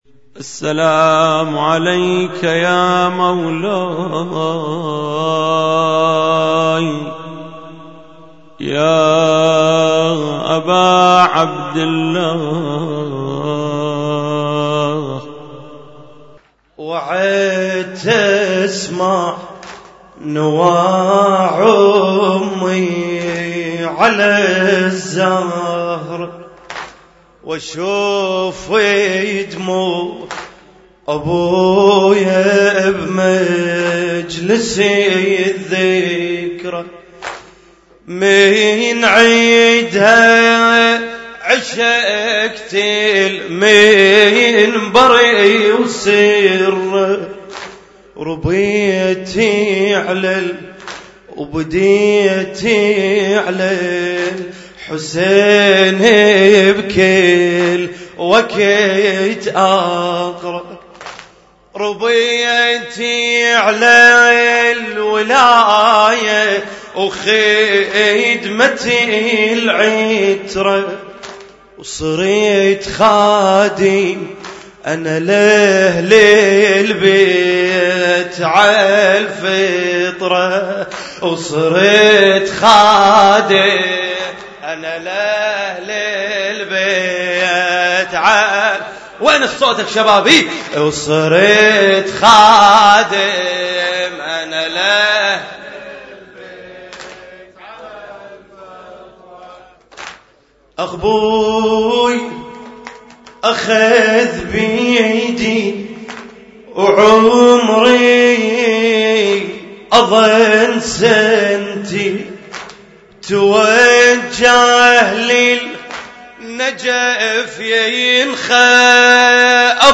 Husainyt Alnoor Rumaithiya Kuwait
لطم ليلة 6 محرم 1435